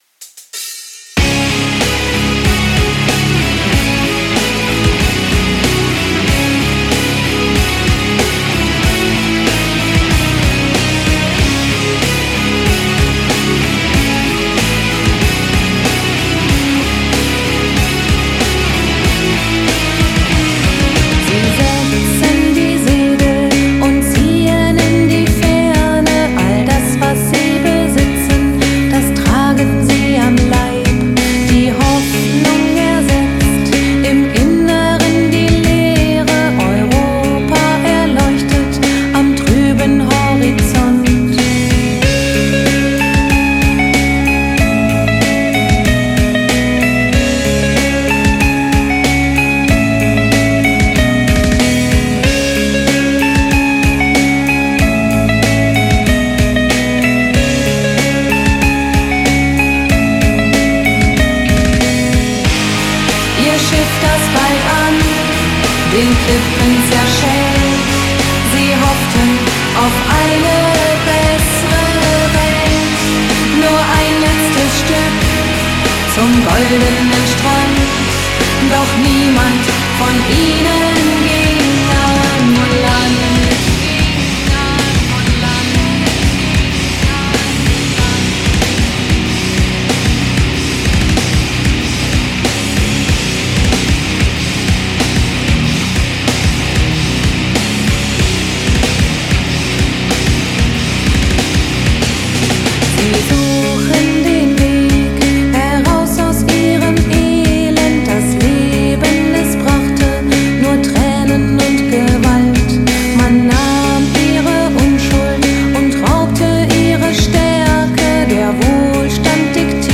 nemeckiq_rok___Goldener_Strand.mp3